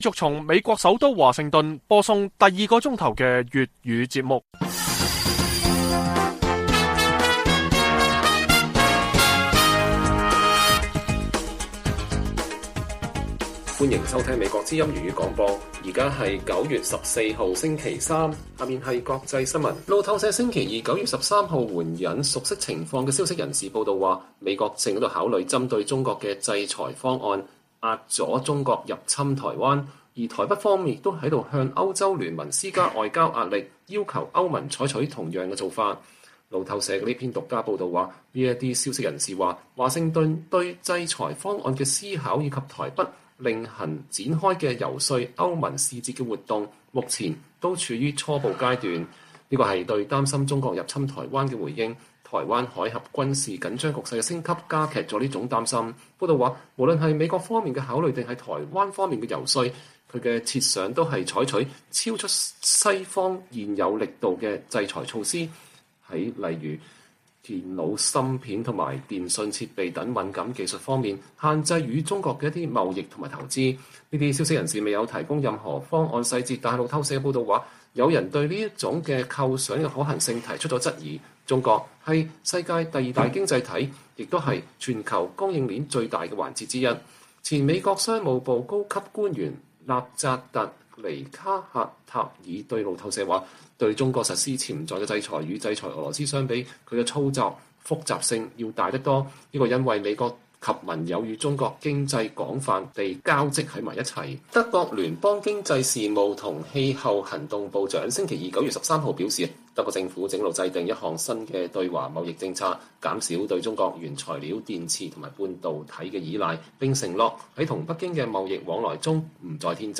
粵語新聞 晚上10-11點： 歐盟委員會主席馮德萊恩訪基輔討論歐盟對烏克蘭的支持